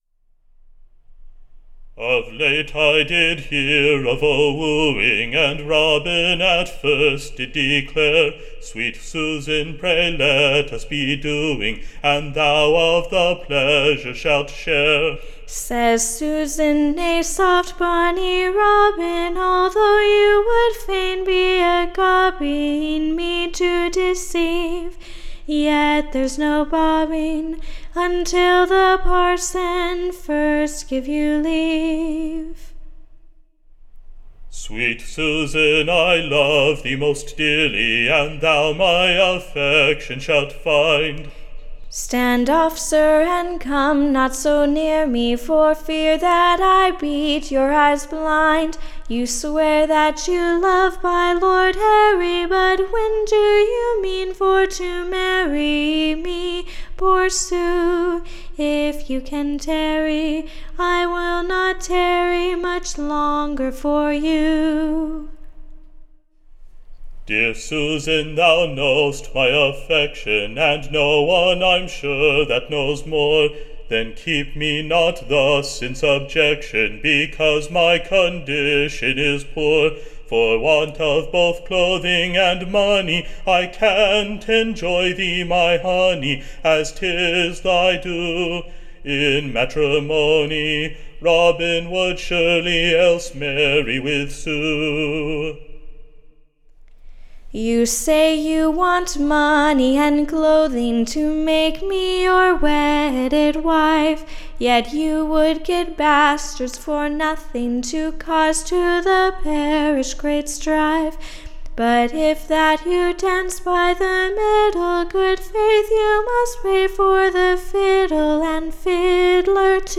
Recording Information Ballad Title The Quarrelsome Lovers: / Or, The Succesless VVoeing. / Being a most Pleasant Song by way of Dialogue between Robin and Susan.